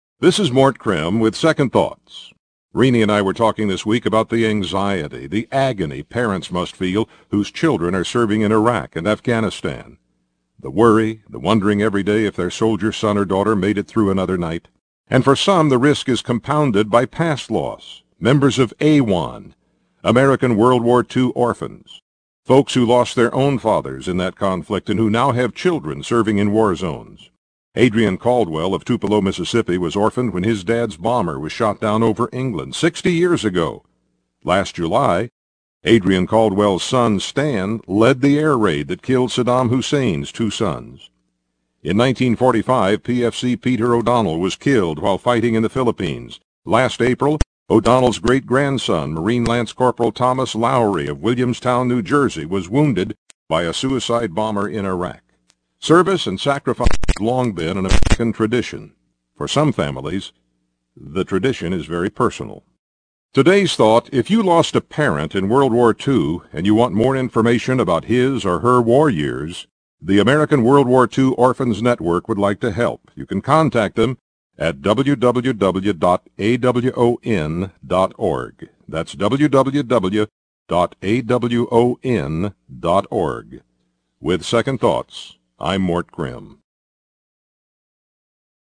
criminterview.mp3